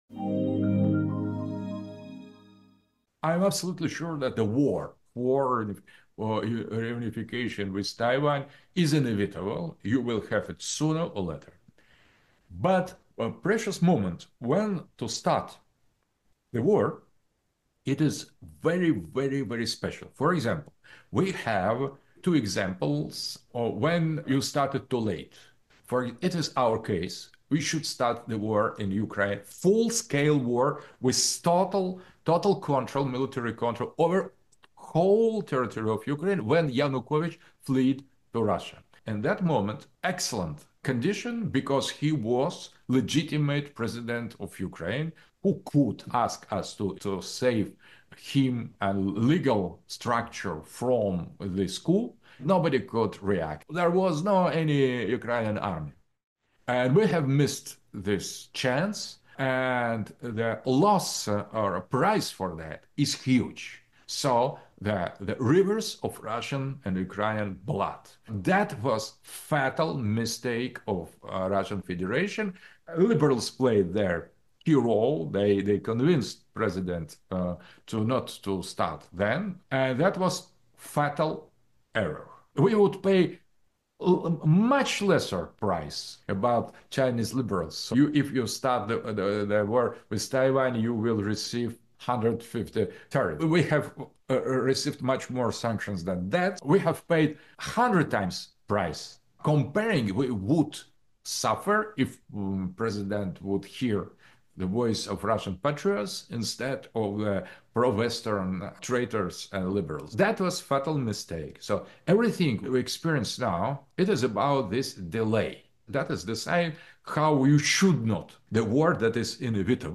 Aleksander Dugin, a prominent Russian political scholar, was asked about his views on the Taiwan issue.